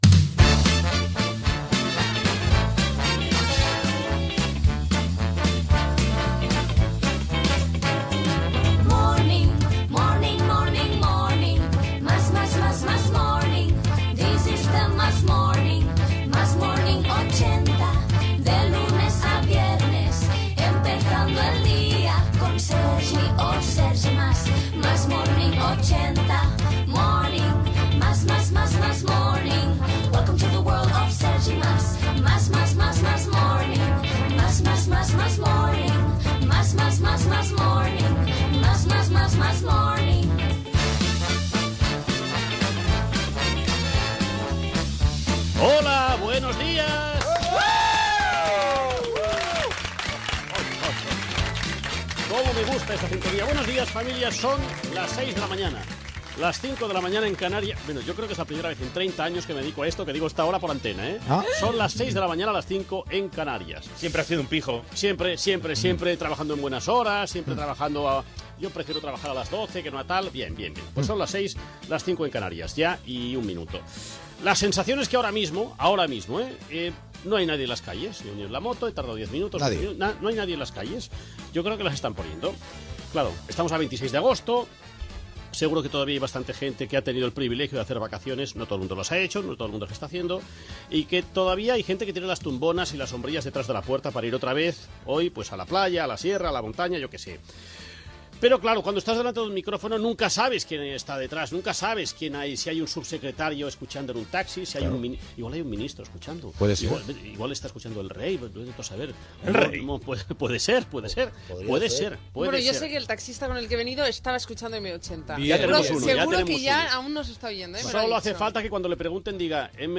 Sintonia cantada del programa, hora, comentari sobre labuidor dels carrers i qui pot estar escoltant el programa, agraïments, comentaris diversos, salutació de l'equip i tema musical
Entreteniment
FM